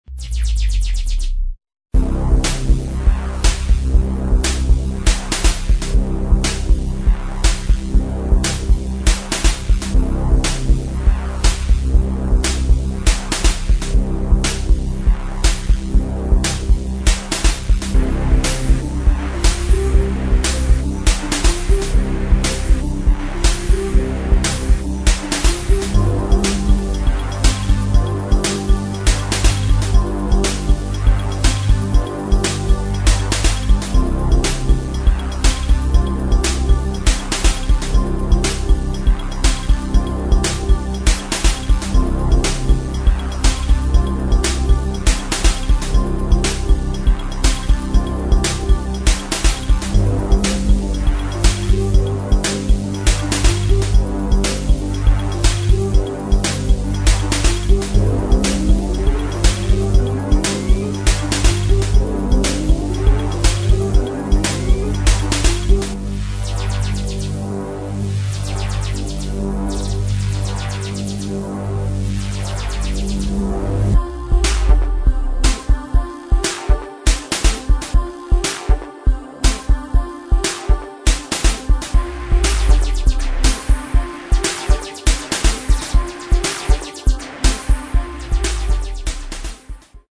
[ DEEP HOUSE / DEEP MINIMAL / COSMIC DISCO ]